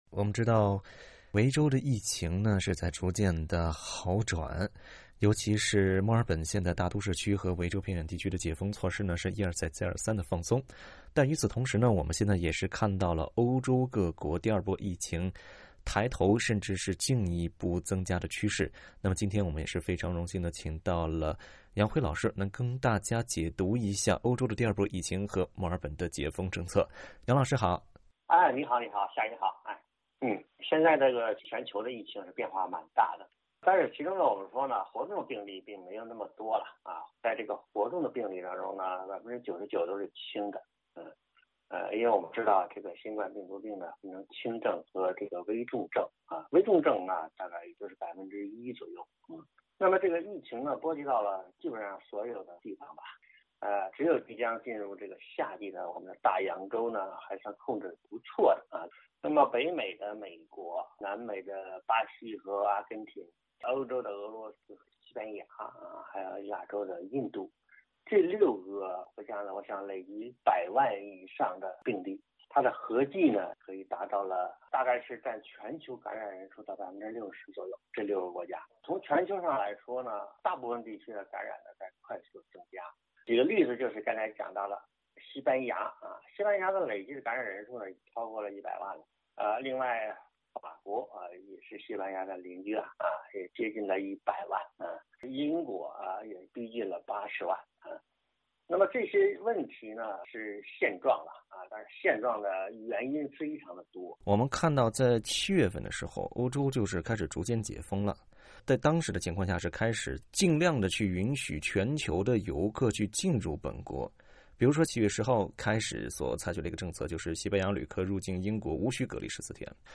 欧洲各国在短暂的小范围开放国界之后，遭遇疫情的反弹。 （欢迎点击图片音频，收听采访。）